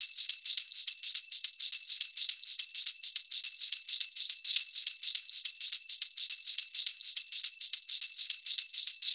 RI PERC 1.wav